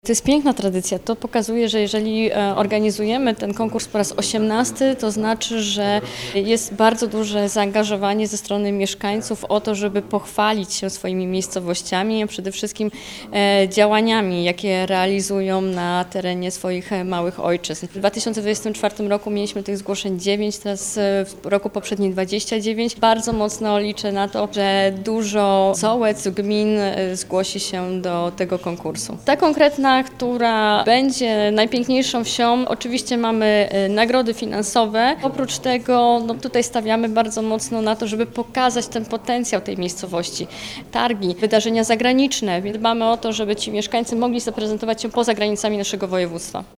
– Konkurs pokazuje zaangażowanie ze strony samorządów i mieszkańców, którzy chcą pochwalić się swoimi miejscowościami oraz działaniami, jakie realizują na terenie małych ojczyzn, podkreśla Natalia Gołąb, członkini zarządu Województwa Dolnośląskiego.